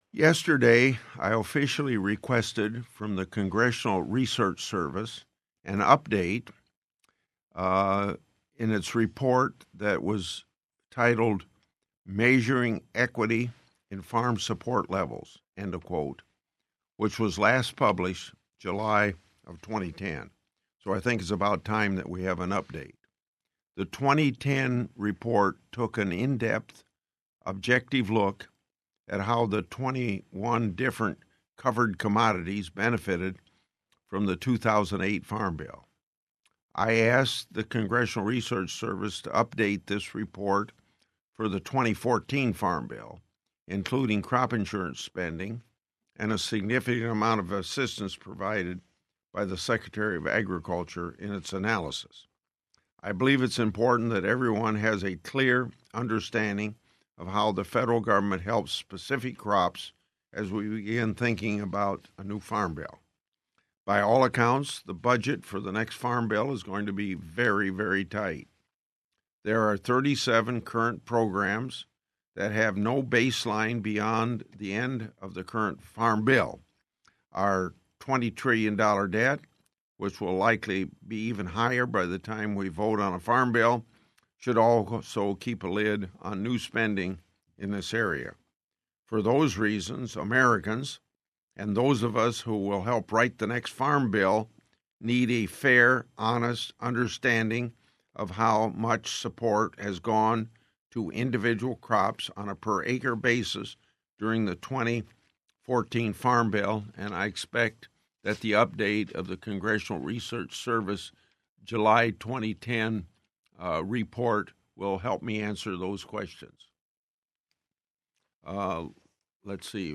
Farm Broadcasters' Network Call